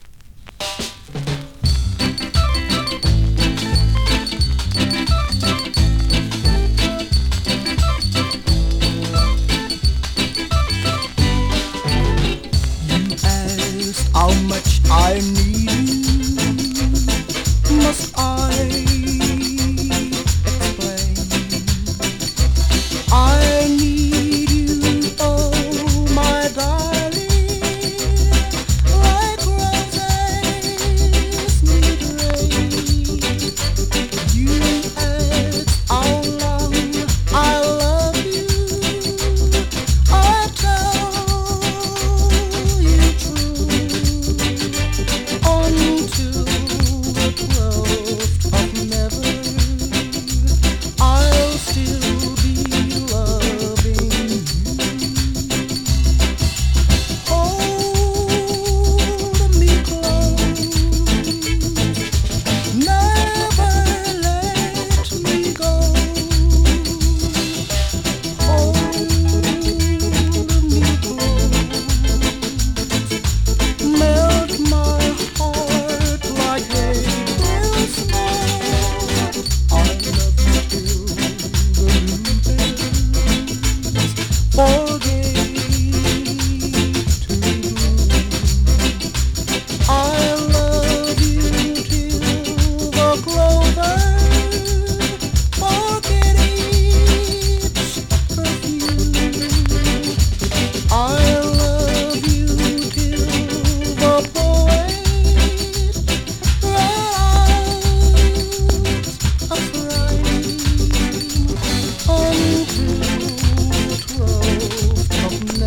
NEW IN!SKA〜REGGAE
スリキズ、ノイズ比較的少なめで